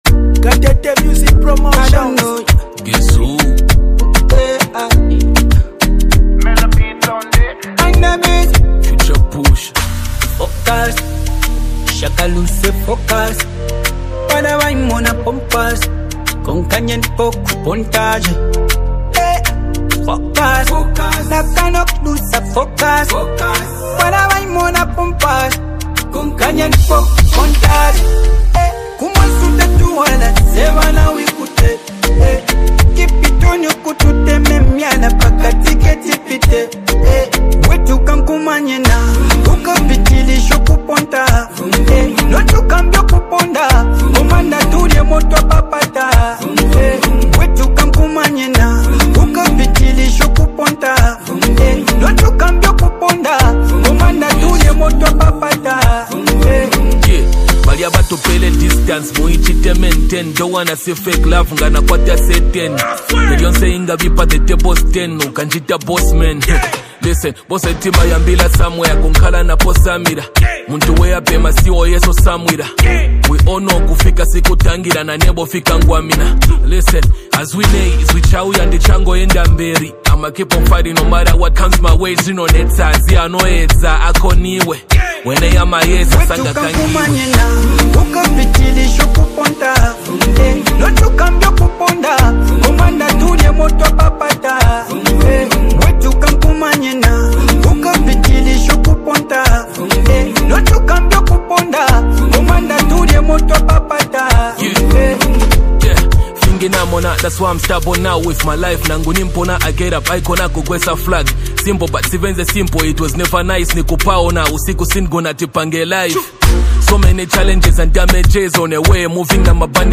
a soulful and catchy hook